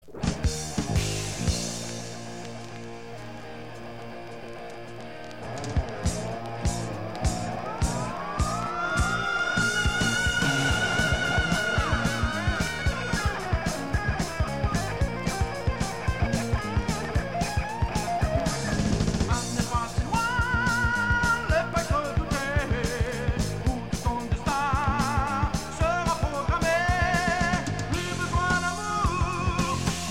Hard rock
Hard heavy Unique 45t retour à l'accueil